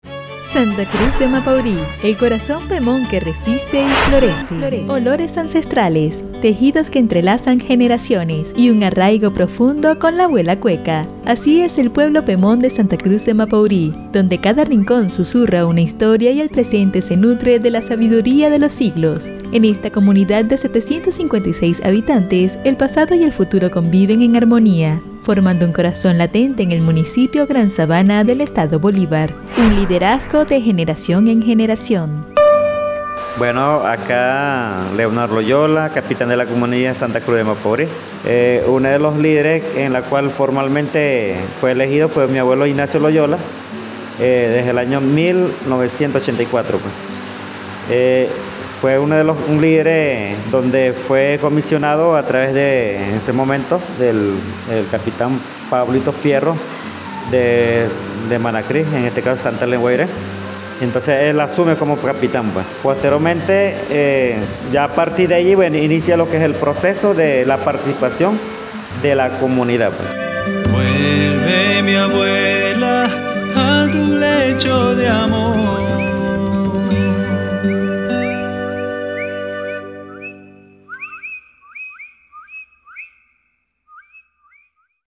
Micros radiales